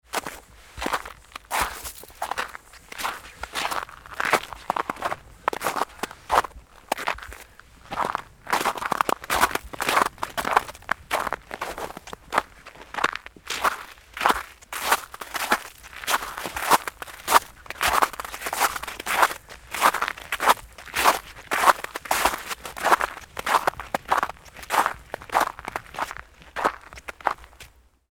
Crunching Steps On Solid Frozen Snow Sound Effect
A human walks outdoors across frozen snow, creating crisp, crunchy steps with every stride in a cold winter environment. This high-quality walking footsteps sound effect captures sharp icy crunch tones, realistic outdoor ambience, and detailed winter atmosphere.
Crunching-steps-on-solid-frozen-snow-sound-effect.mp3